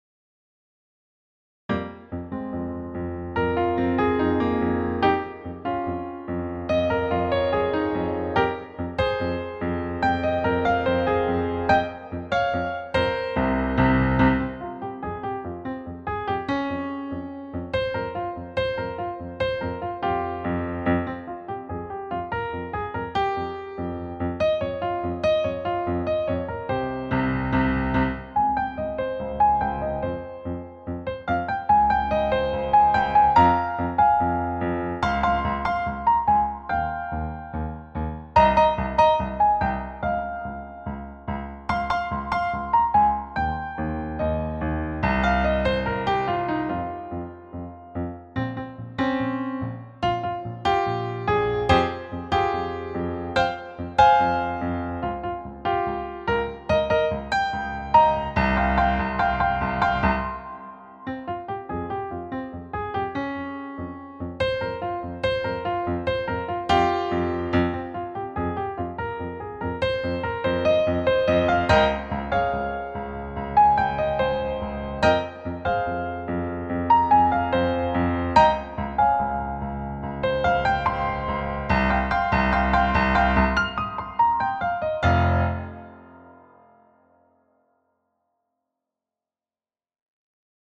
• Key: F Natural Minor
• Time signature: 4/4
• Accents and dynamic contrasts to shape dramatic nuance